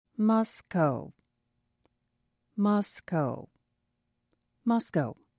"What's Hot" includes pronunciations of names and topics that are relevant in recent news.
Mekelle MEH kell eh